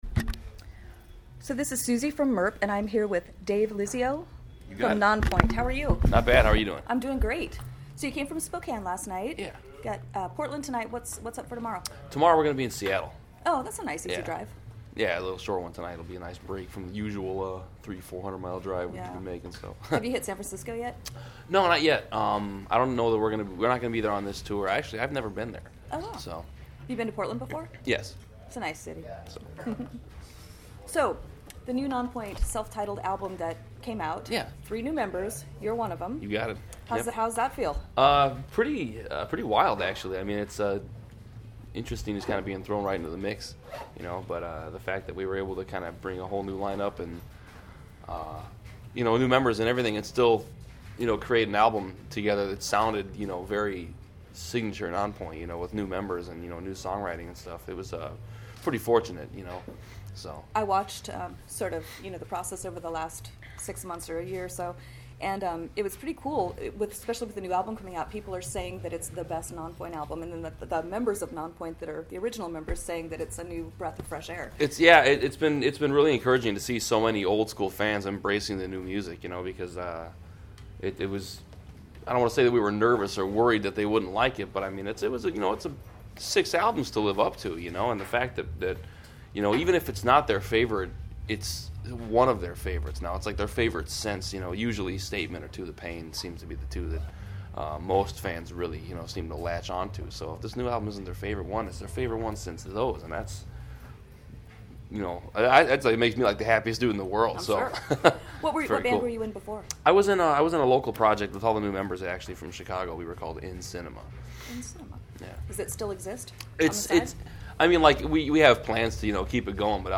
Interview with Nonpoint - Portland, Oregon - 2018 | MIRP
Venue: Portland, Oregon
Topic: Interview